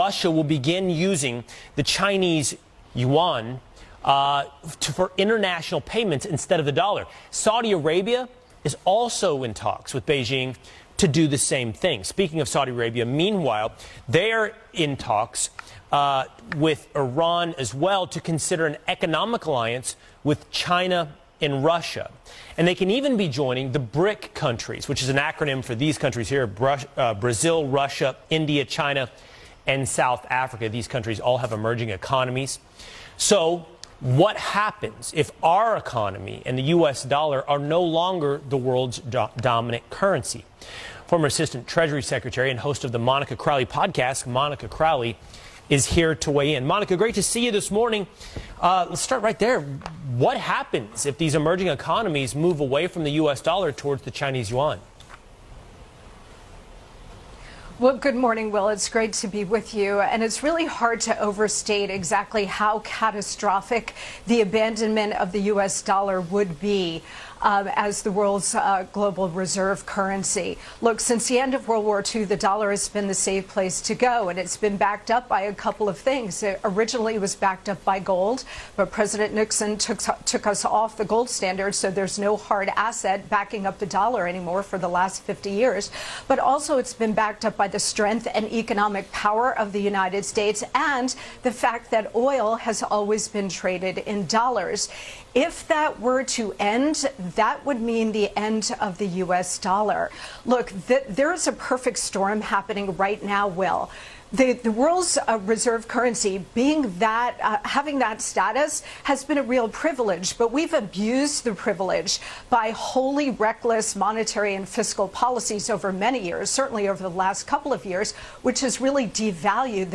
Was würde passieren wenn der Dollar nicht mehr die Weltreservewährung wäre? Die Antwort im Interview: Es wäre eine...